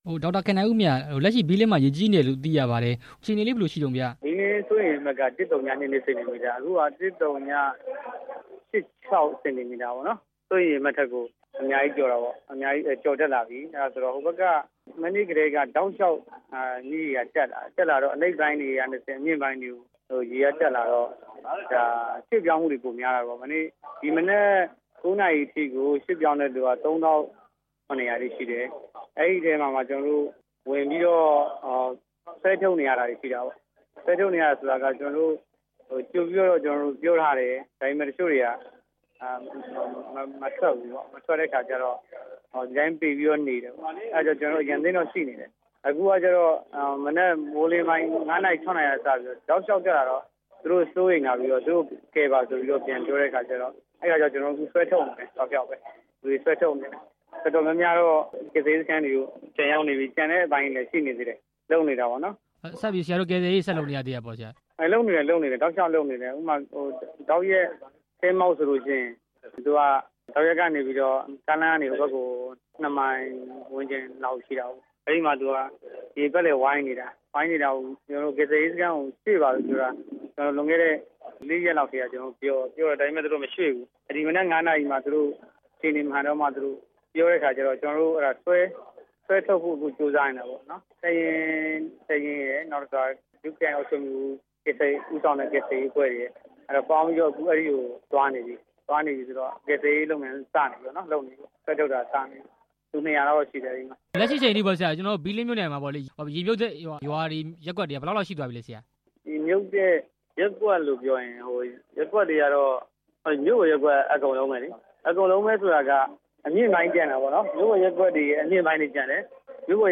ဘီးလင်းရေကြီးနေမှု ဆက်သွယ်မေးမြန်းချက်
မေးမြန်းခန်း